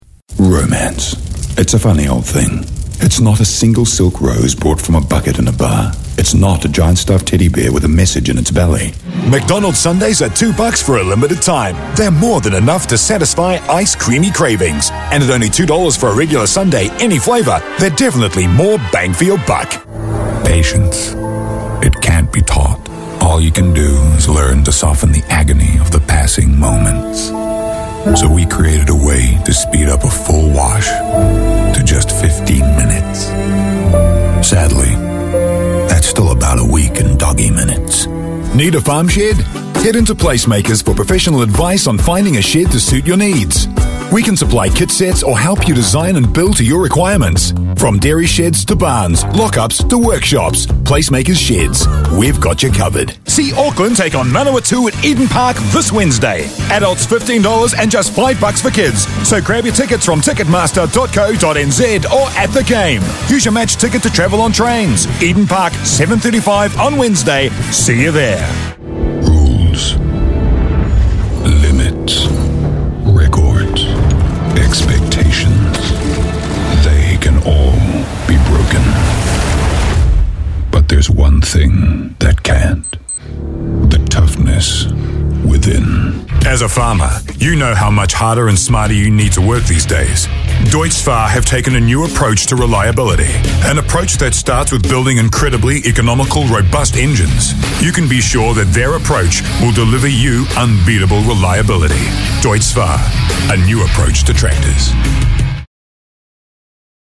I can move in many directions depending on the read required.
A deep, warm and smooth voice that can be authoritative, provocative, or gently persuasive, for Radio & TV Commercials, Epic Movie Trailers, Corporate Narrations, Documentaries, Explainers, IVR's, Video Game Tr...